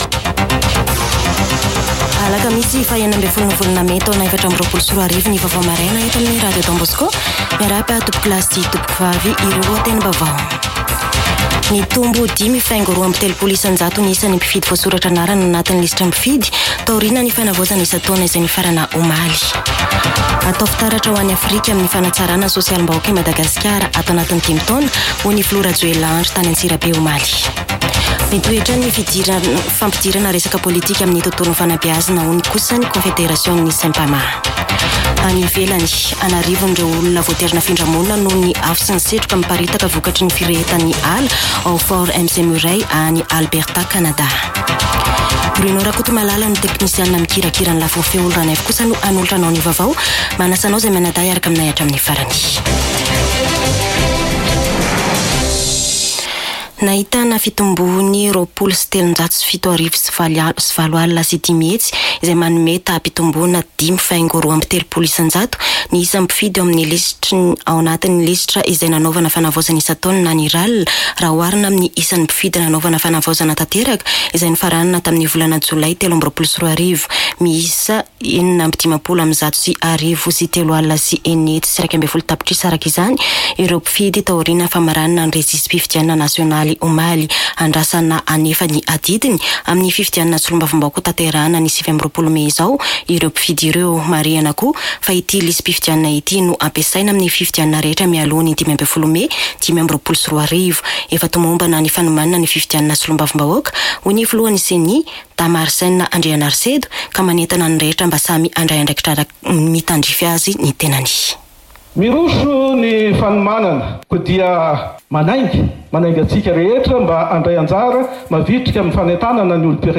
[Vaovao maraina] Alakamisy 16 mey 2024